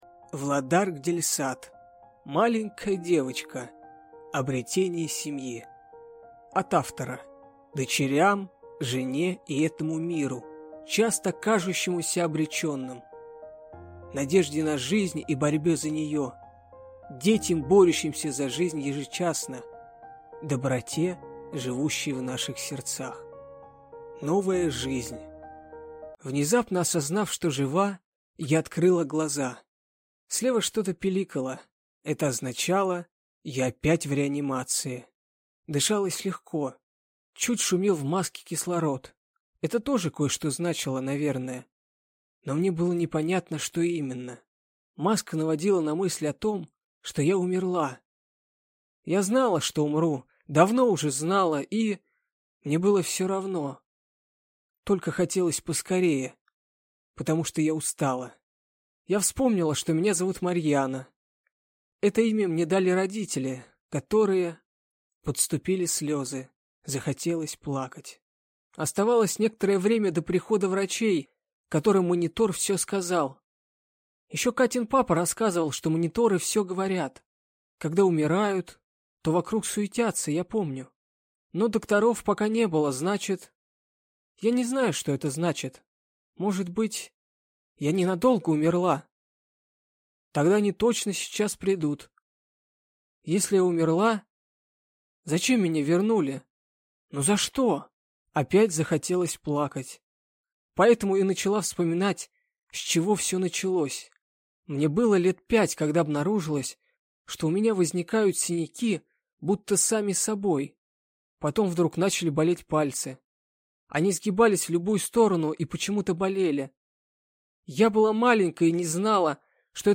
Аудиокнига Маленькая девочка. Обретение семьи | Библиотека аудиокниг